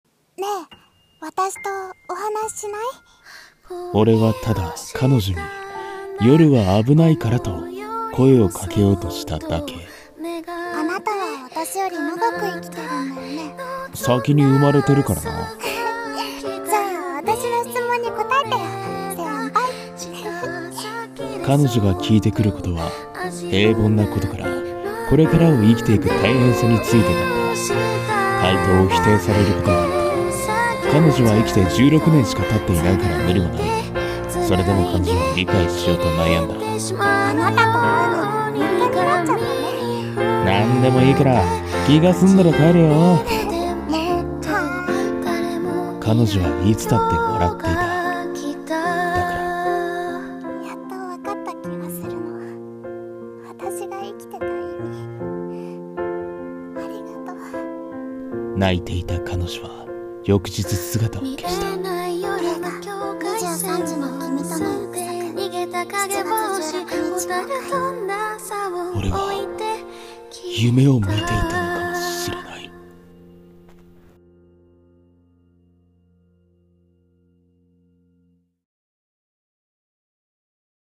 映画予告風声劇「23時の君との約束